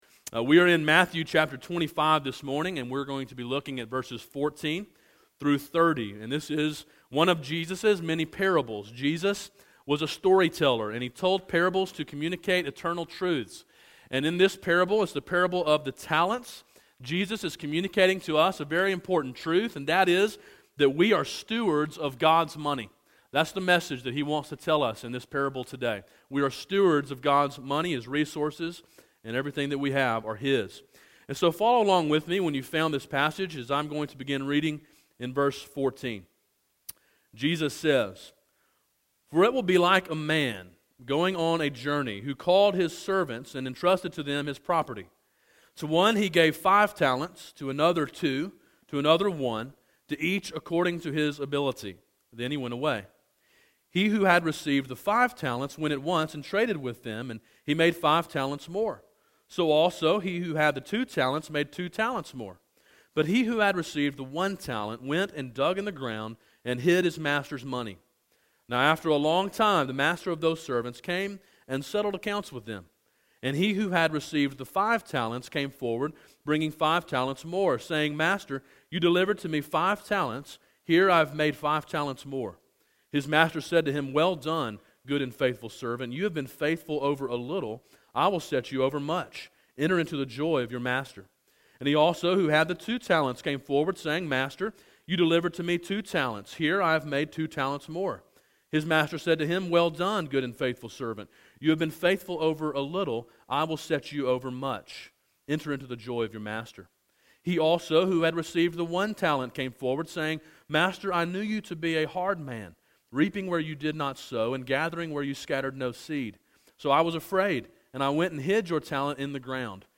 A sermon in a series titled We Give.